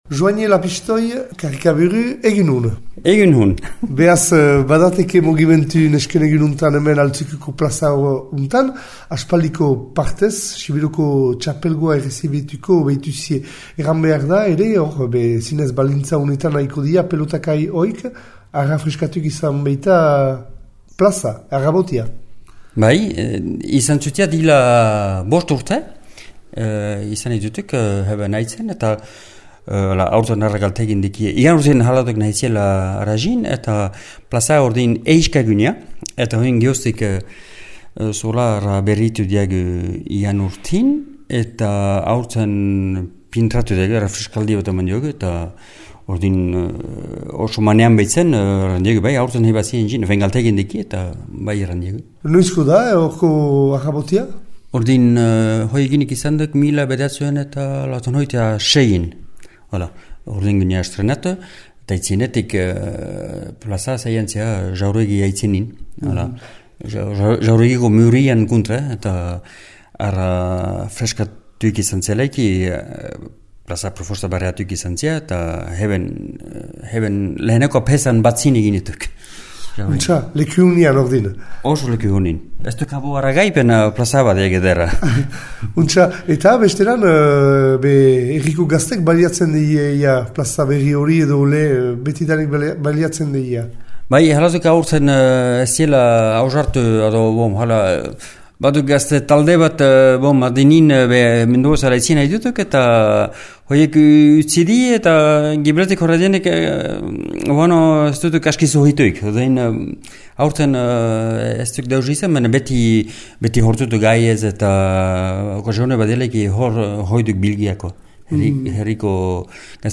Paradaz baliatü gira Johañe Lapixtoi Carricaburu Altzürüküko aüzapezarekin hitz egiteko, entzün :